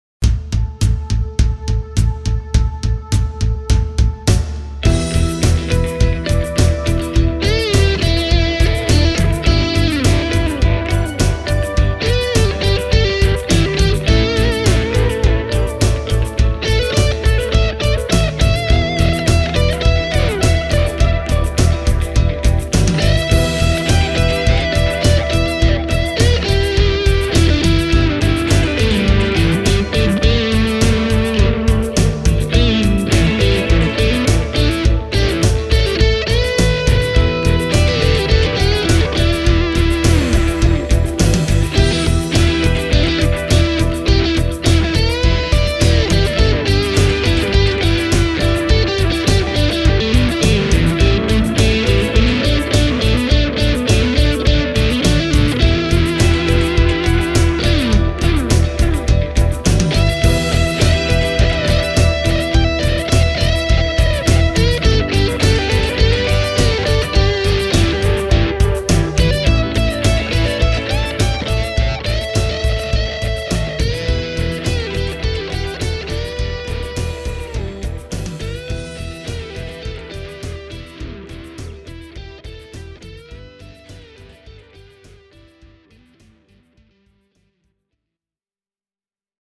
Äänitin T-Rex Replicatorilla kaksi erityylistä demobiisiä, joista voi kuulla uutuuslaitteen soundeja bändisovituksen kontekstissa.